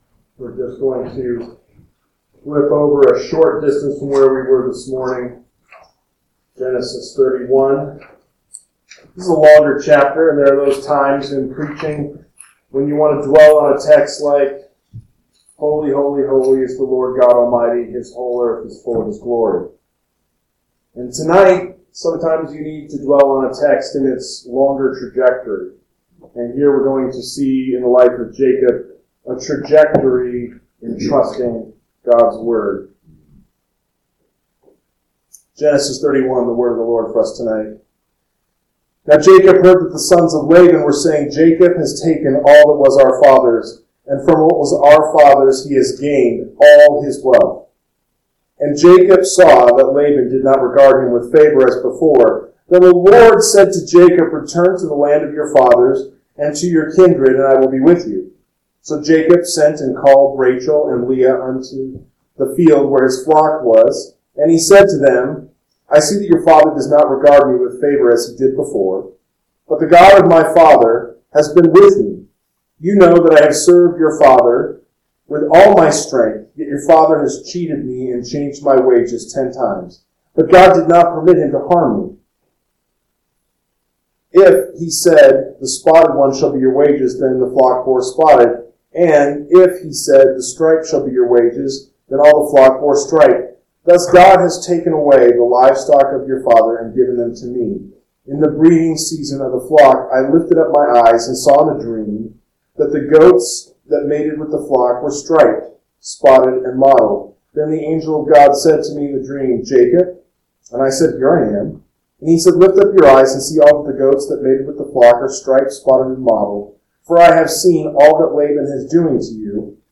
PM Sermon – 11/30/2025 – Genesis 31 – Northwoods Sermons